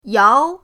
yao2.mp3